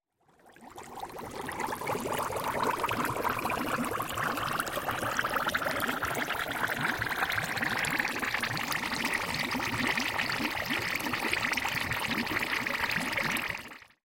Звуки кислоты
Кипение едкого вещества